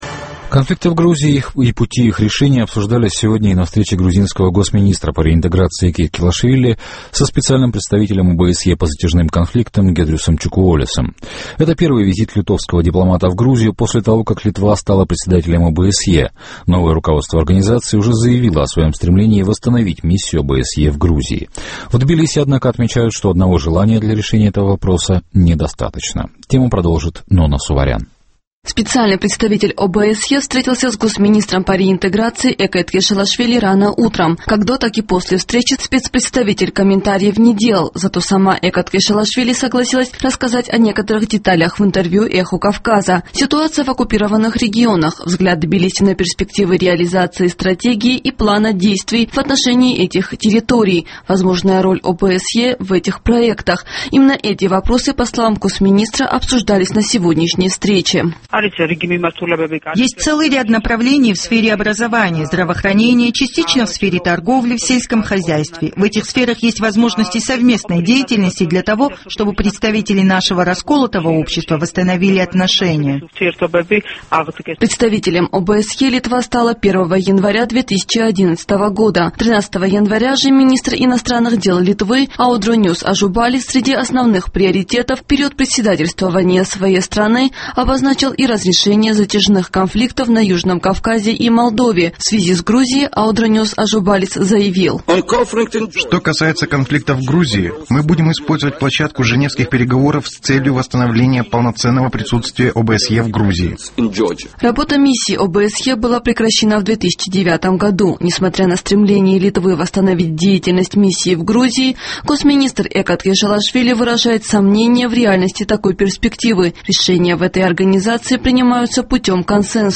Зато сама Эка Ткешелашвили согласилась рассказать о некоторых деталях в интервью «Эху Кавказа».